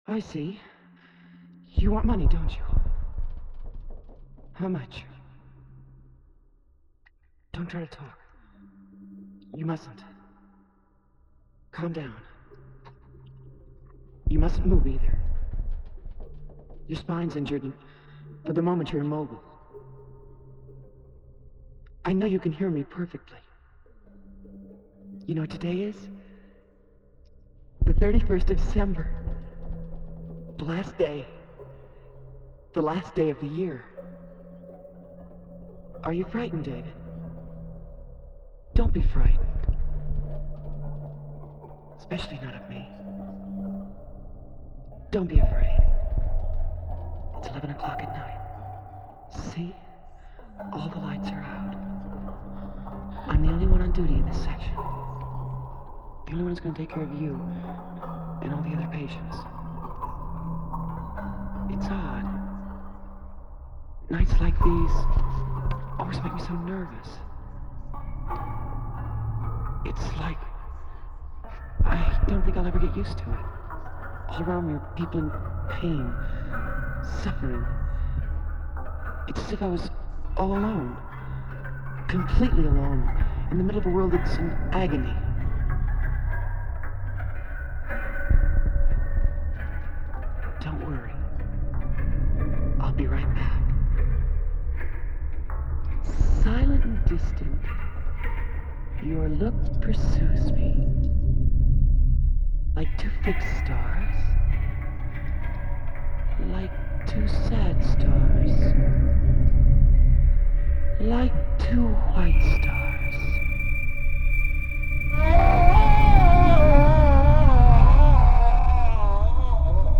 Inspired by a random sample from the movie Eye of the Beholder (1999) (even though I didn't know the title of the movie at the time when I made the first version)